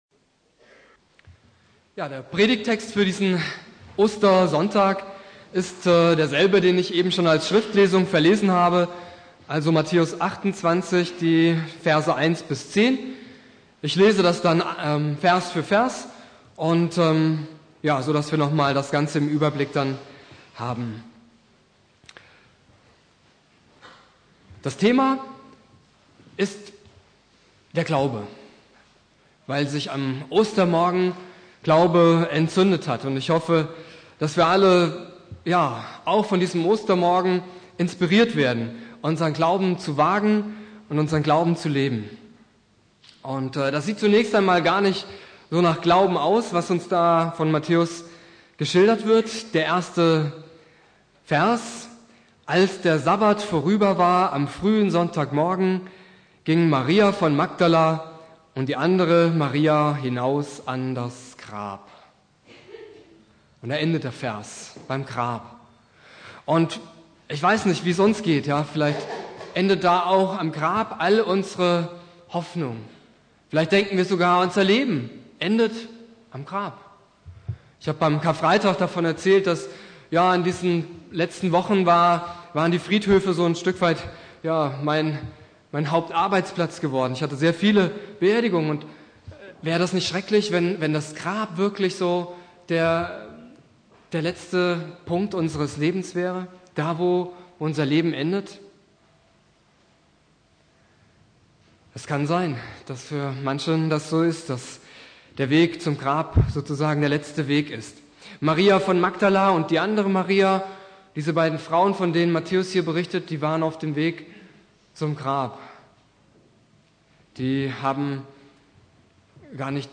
Predigt
Ostersonntag Prediger